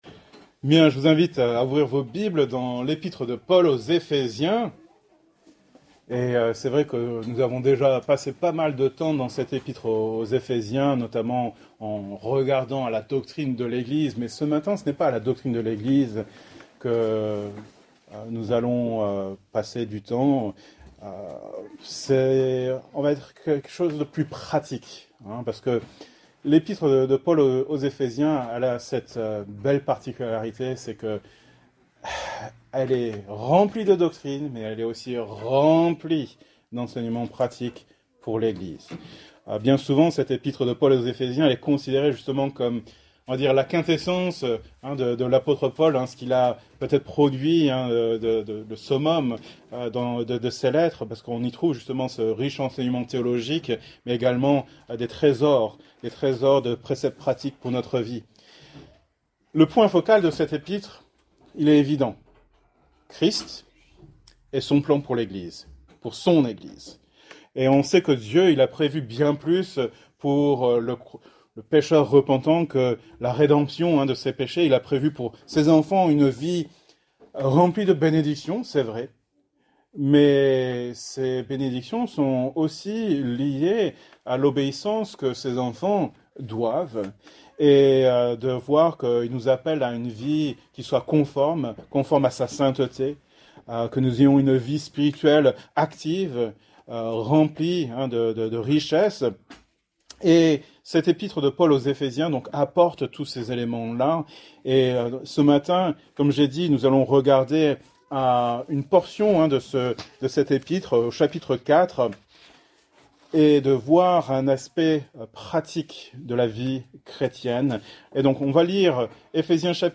Thème: Grossièreté , Vulgarité Genre: Prédication